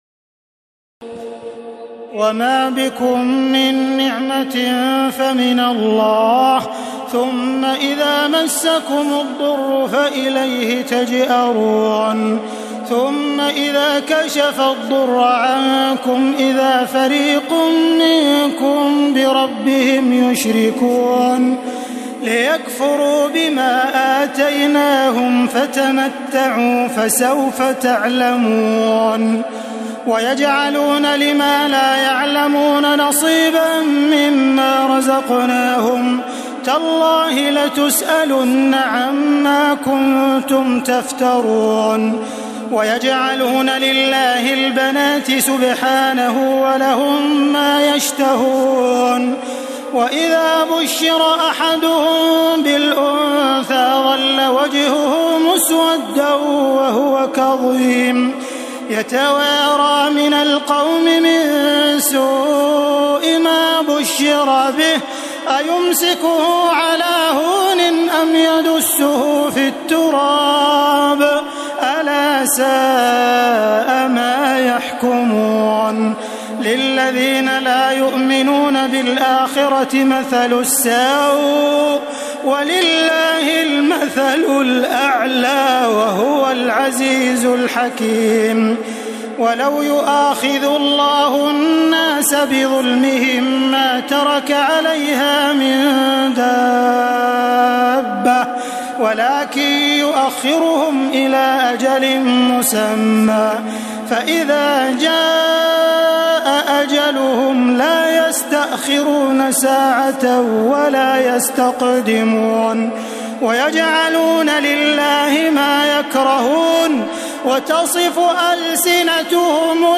تراويح الليلة الثالثة عشر رمضان 1432هـ من سورة النحل (53-128) Taraweeh 13 st night Ramadan 1432H from Surah An-Nahl > تراويح الحرم المكي عام 1432 🕋 > التراويح - تلاوات الحرمين